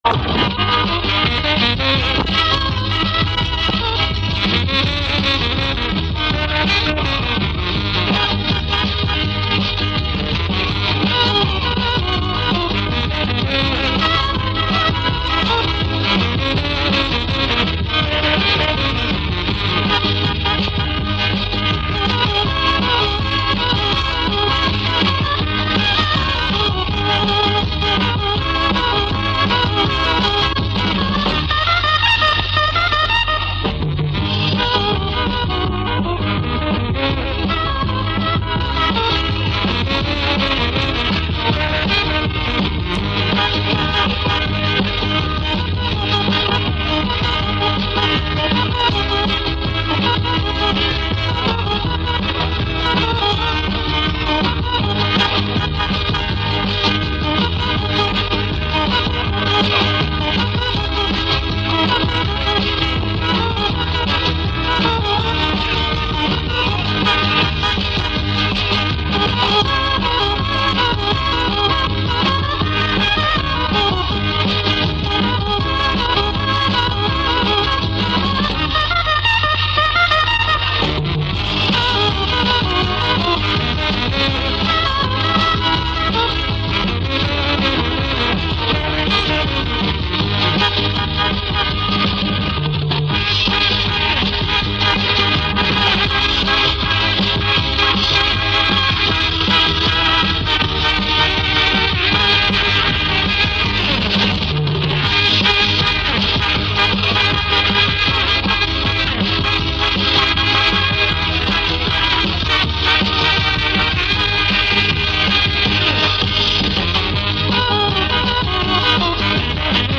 Есть похожая, только темп быстрее.